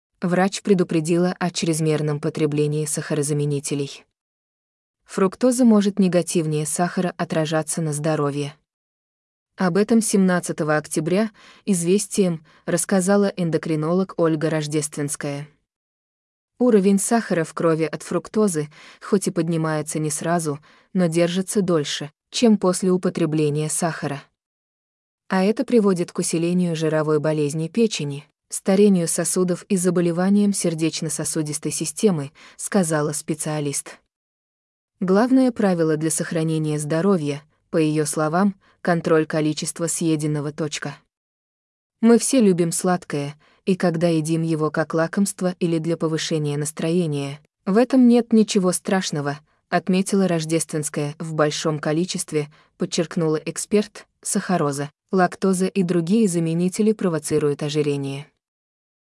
скачать интервью в docx формате